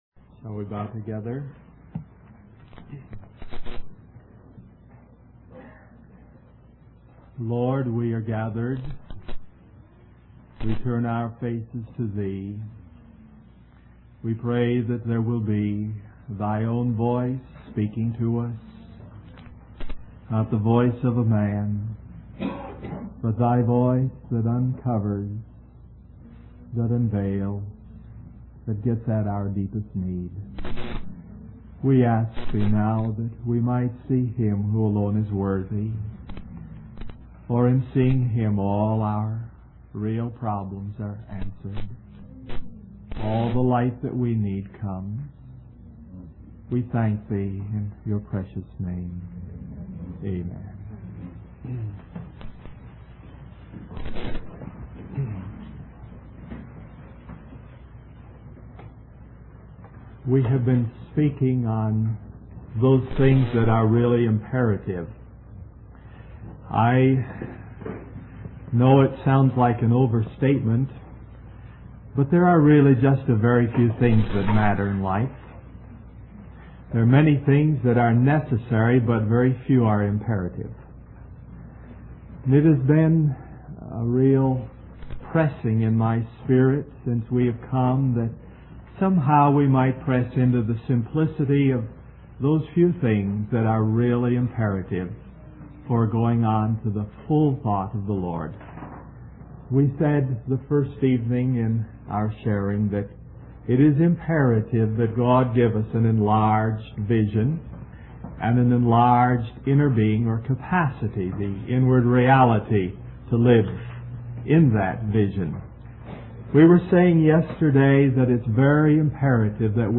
1969 Wabanna (Atlantic States Christian Convocation) Stream or download mp3 Summary God is calling us to see things from His standpoint and perspective. This will only come as we enter into the Holy Place and receive inward revelation from the Lord.